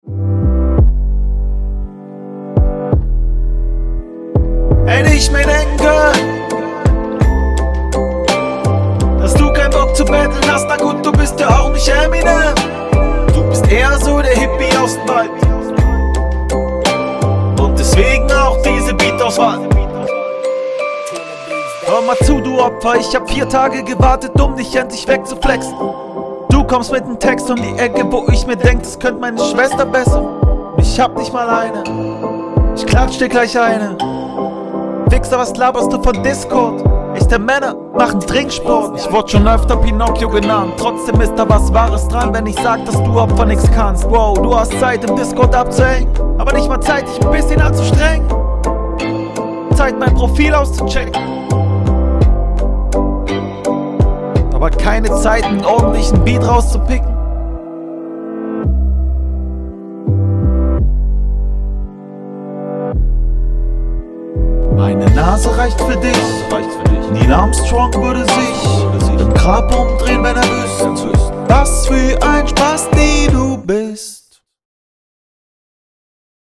Flowlich auch ganz komisch, ist offensichtlich auch nicht dein Beat gewesen.